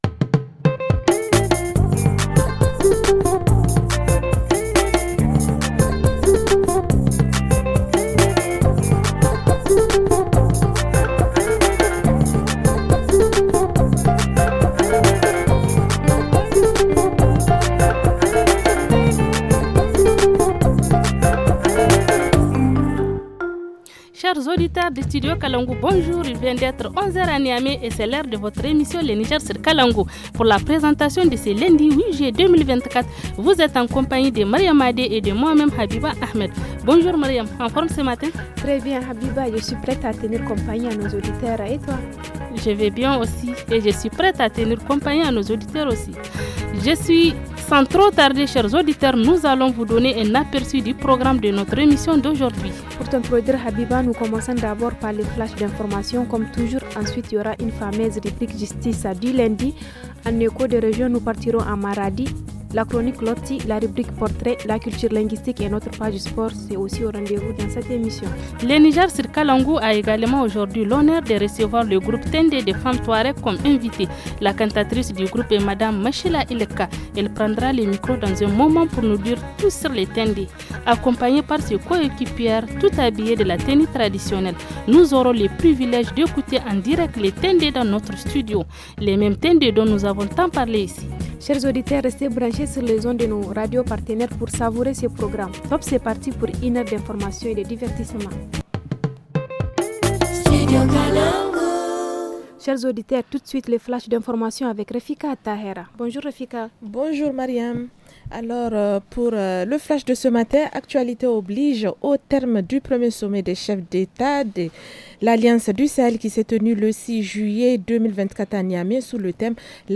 Dans l’émission de ce 8 juillet : le groupe « Tindé » des femmes touarègues d’Agadez en studio. Qu’est-ce qu’une comparution sur reconnaissance préalable de culpabilité ? À Maradi, des jeunes filles se spécialisent en électricité pour bâtiment.